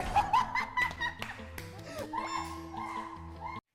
wooyoung laugh